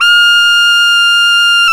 SAX TENORF21.wav